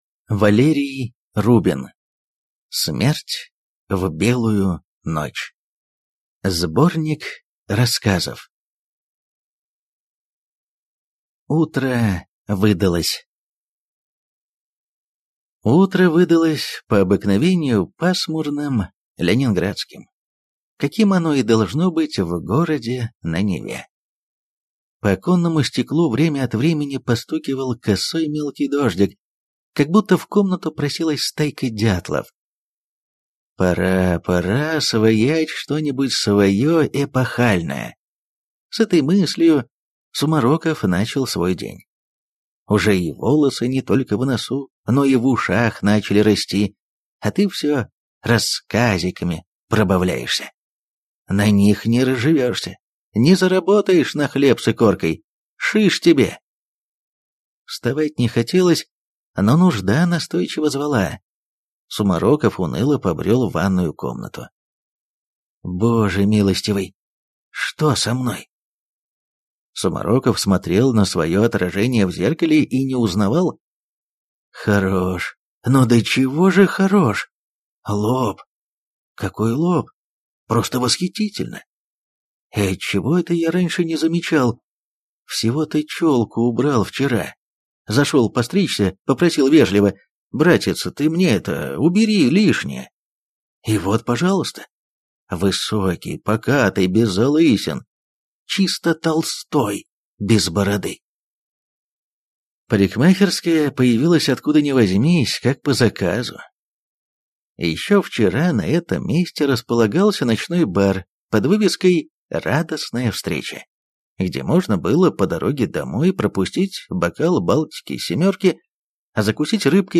Аудиокнига Смерть в белую ночь | Библиотека аудиокниг